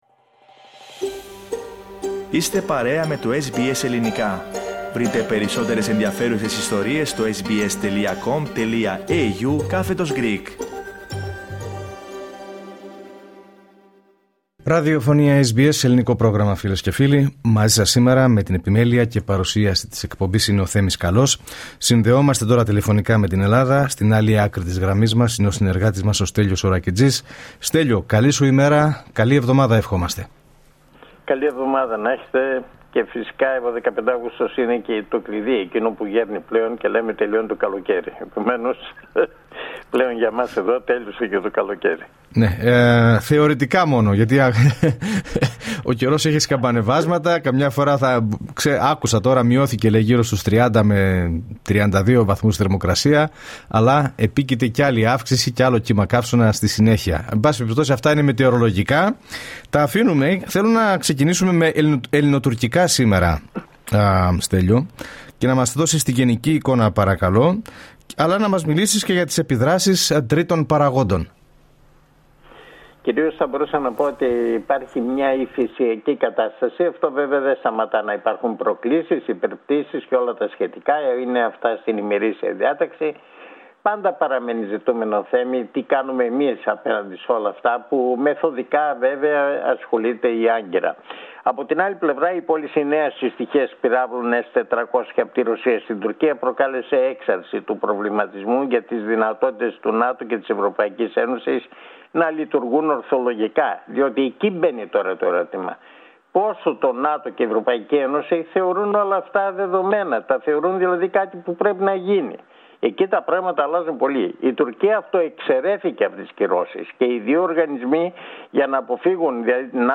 Ακούστε την εβδομαδιαία ανταπόκριση από την Ελλάδα.